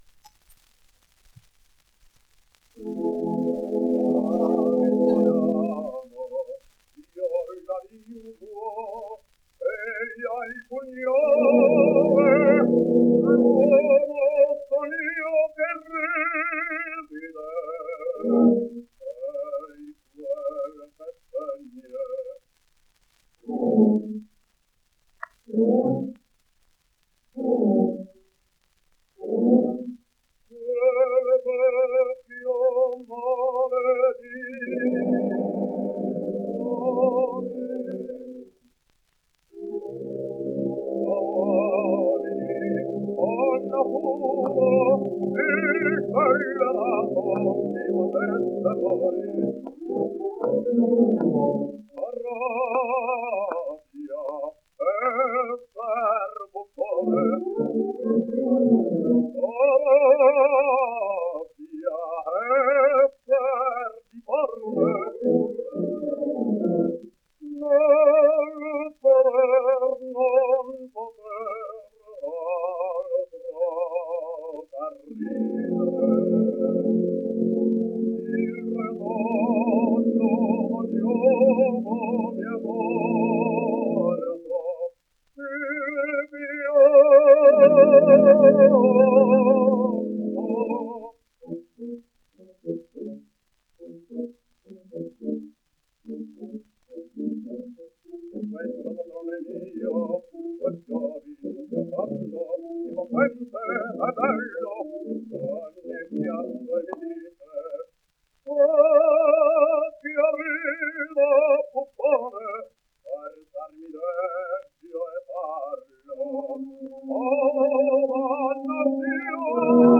Rigoletto: Parisiamo (sonido remasterizado)
1 disco : 78 rpm ; 25 cm Intérprete: barítono